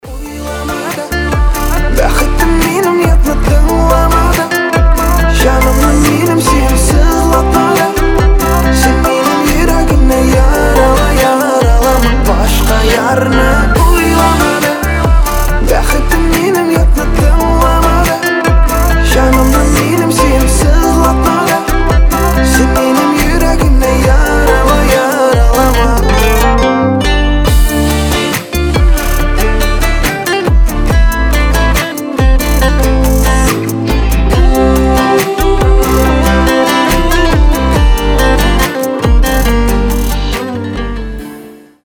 • Качество: 320, Stereo
поп
гитара
мужской вокал
татарские